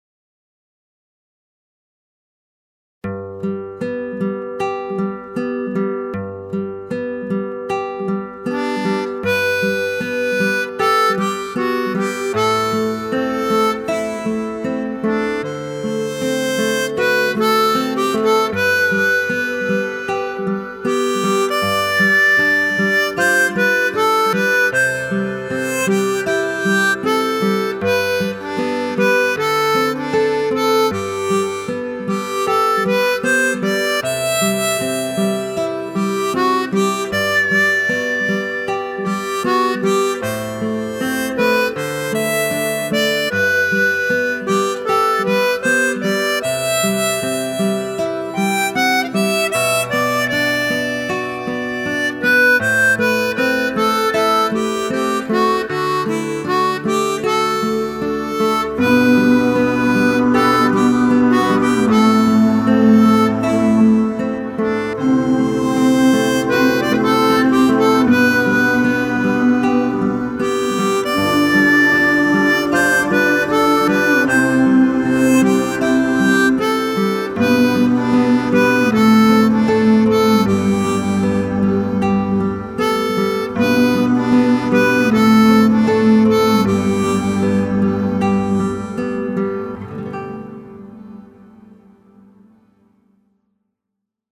هارمونیکا و گیتار کلاسیک